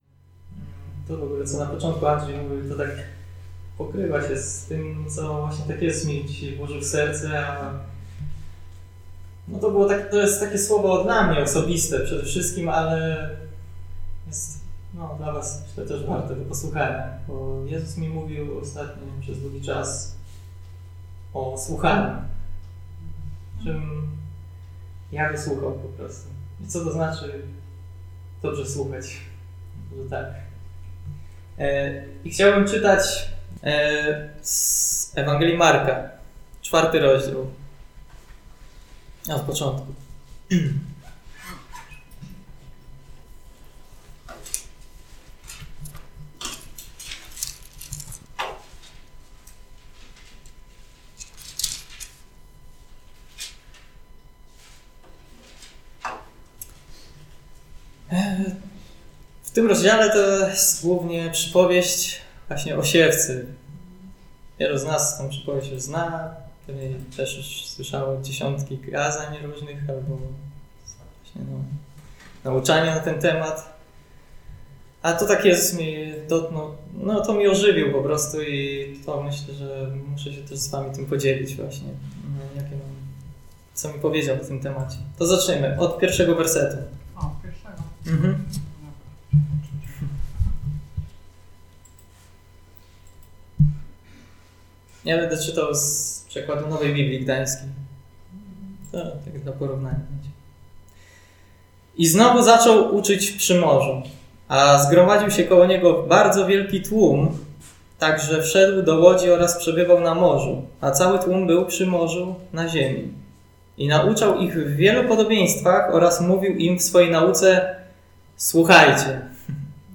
Posłuchaj kazań wygłoszonych w Zborze Słowo Życia w Olsztynie